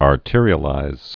(är-tîrē-ə-līz)